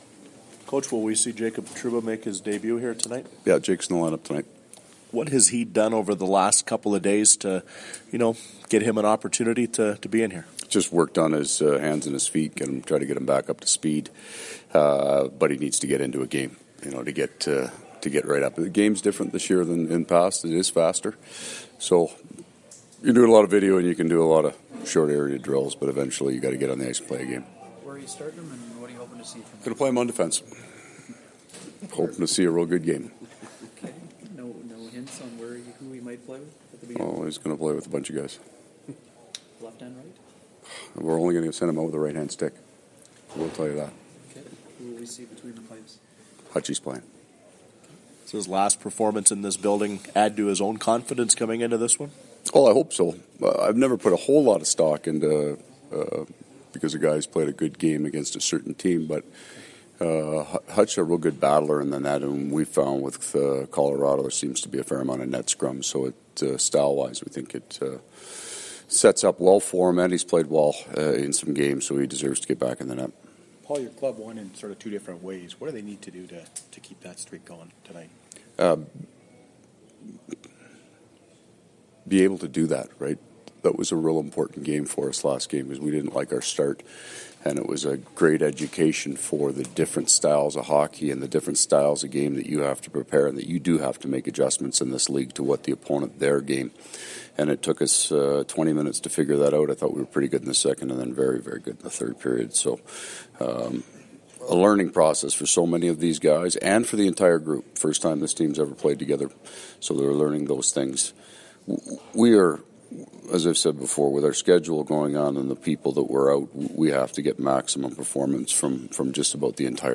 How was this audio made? Audio courtesy of TSN 1290 Winnipeg.